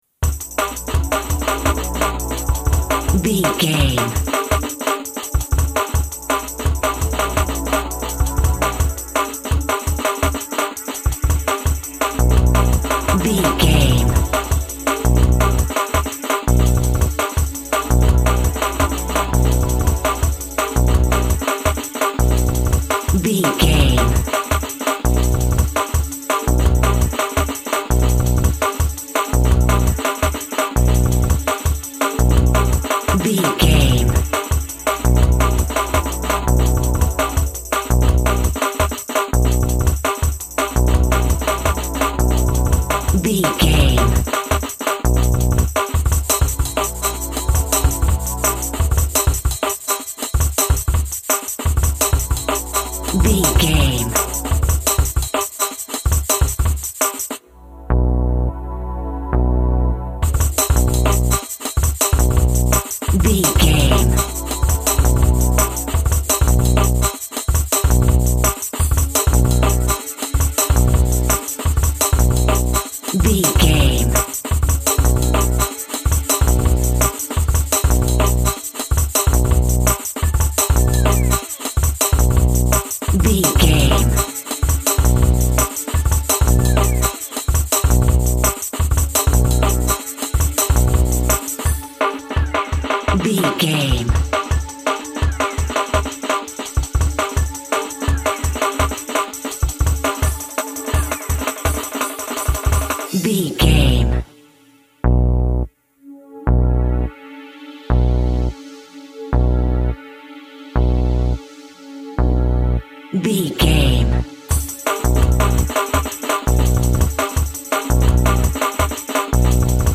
Epic / Action
Fast paced
Atonal
Fast
futuristic
driving
frantic
drums
synthesiser
drum machine
synth lead
synth bass